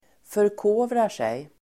Ladda ner uttalet
Uttal: [förk'å:vrar_sej]